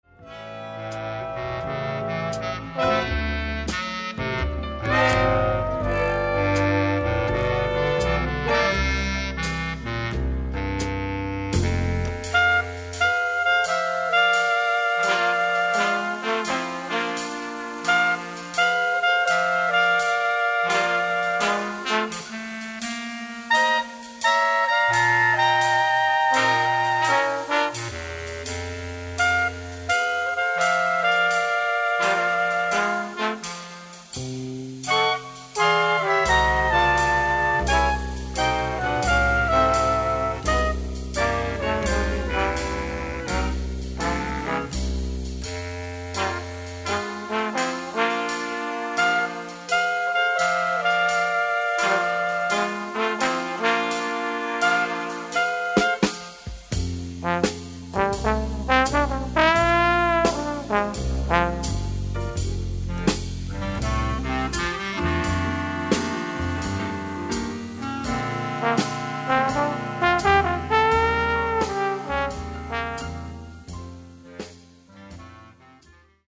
who played piano throughout this 5-album series
vibraphone
cornet
trumpet
trombone
bass
drums
saxophone
clarinet
percussion